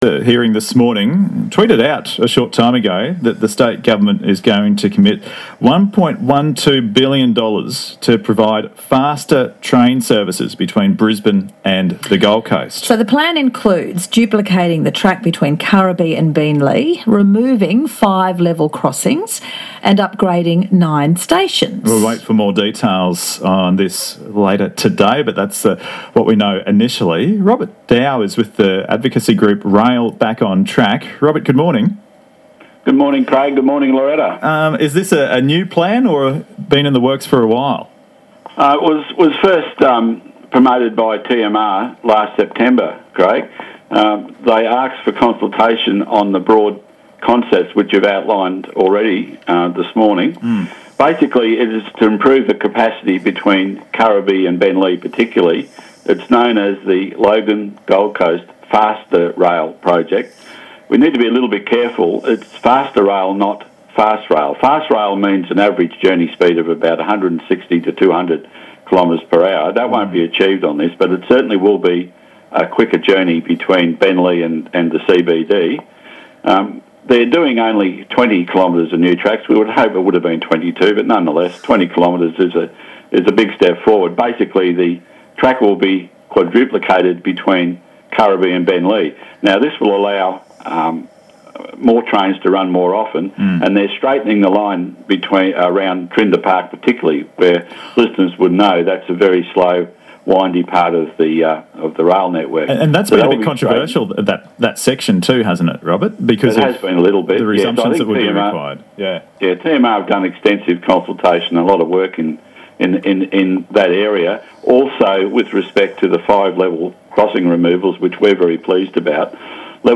Been invited to do an interview shortly (6.40am) ABC Radio Brisbane Breakfast.